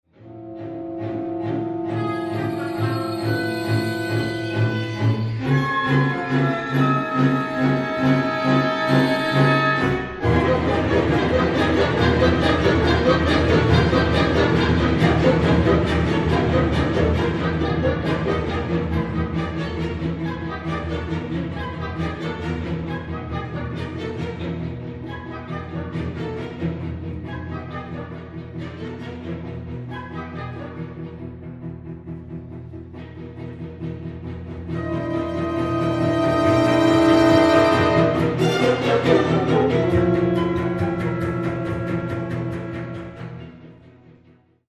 Reminiszenzen f. Orchester (1994)
(1 Fl, 2 Ob, 2 Fg; 2 Hr; Str: 5-5-4-4-2)